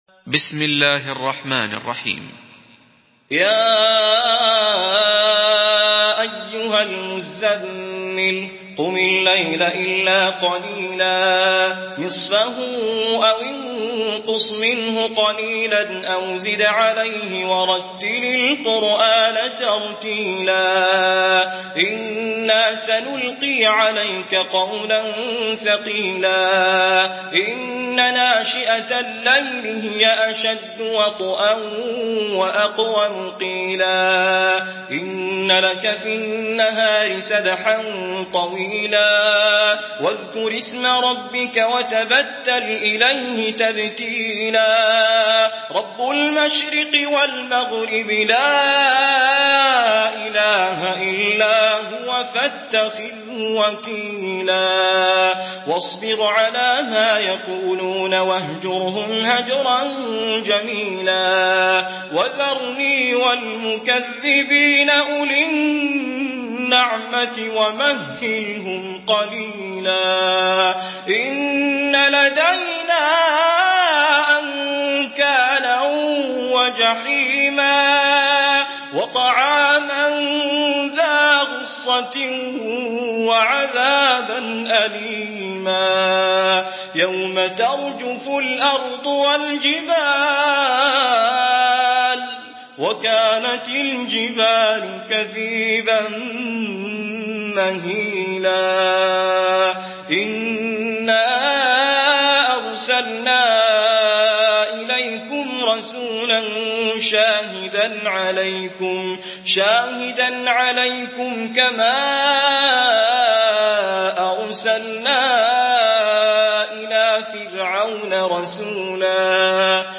سورة المزمل MP3 بصوت توفيق الصايغ برواية حفص
مرتل